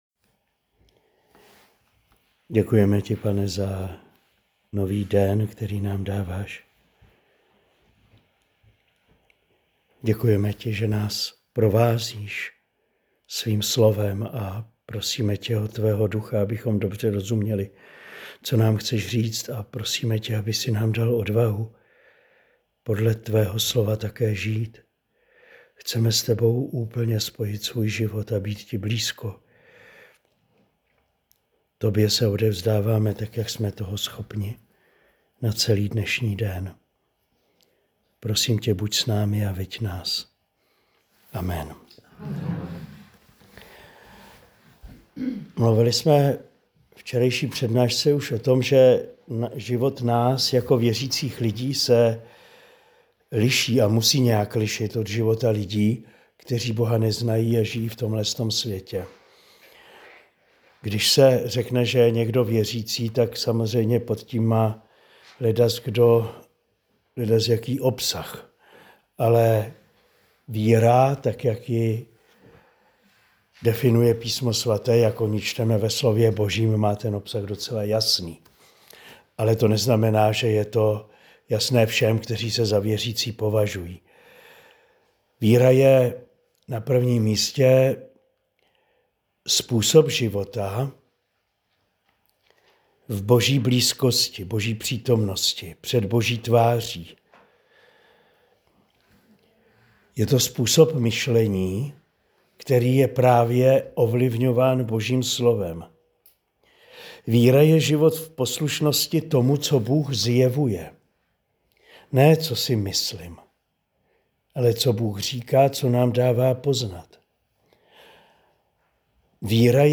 Druhá promluva z duchovní obnovy pro manžele v Kostelním Vydří v únoru 2025.